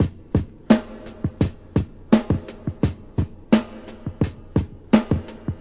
• 86 Bpm Modern Drum Loop F Key.wav
Free breakbeat - kick tuned to the F note. Loudest frequency: 831Hz
86-bpm-modern-drum-loop-f-key-aK7.wav